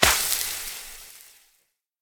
projectile-acid-burn-long-1.ogg